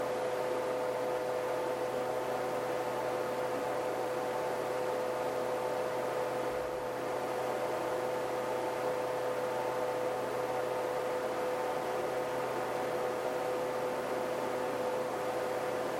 The dominant frequencies are in the 400Hz – 1.25KHz region.
I have recorded the signals shown above, but please keep in mind that I’ve enabled Automatic Gain Control (AGC) to do so to make it easier for you to reproduce them.
50% Fan Speed